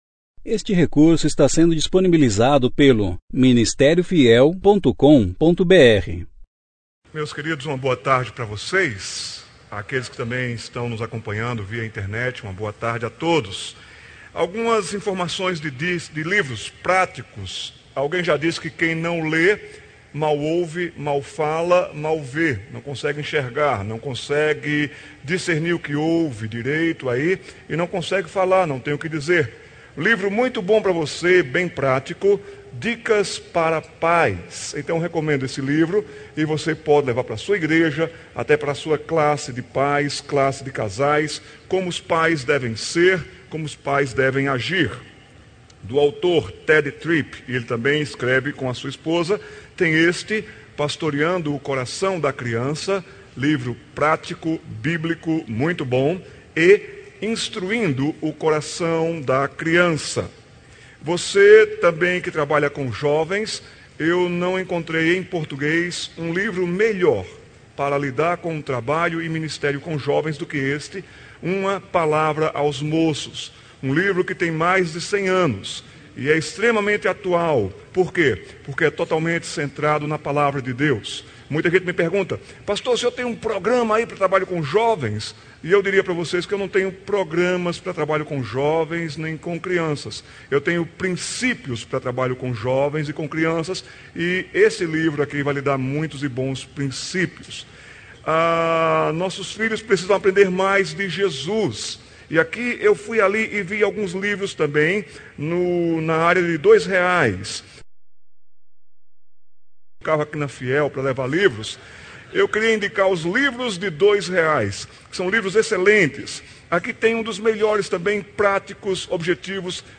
Conferência: 28ª Conferência Fiel para Pastores e Líderes Tema: Alicerces da Fé Cristã – O que me torna um cristão?